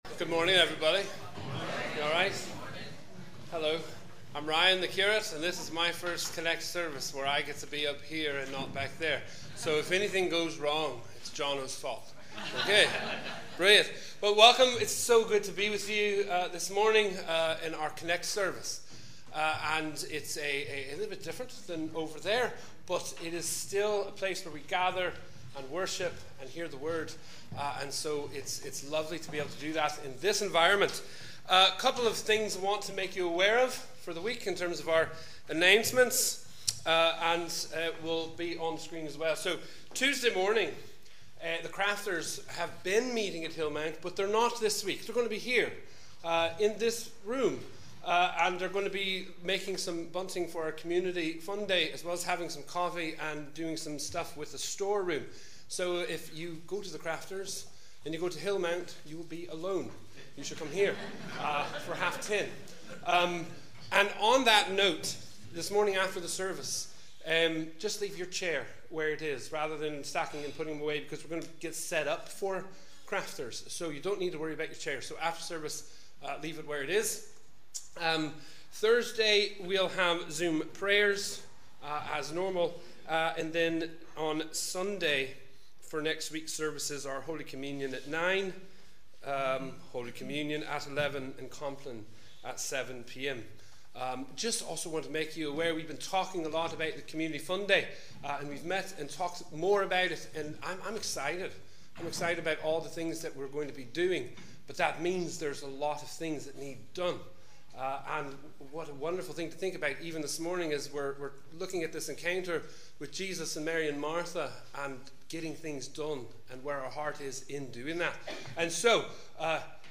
We warmly welcome you to our CONNEC+ service as we worship together on the 7th Sunday after Trinity.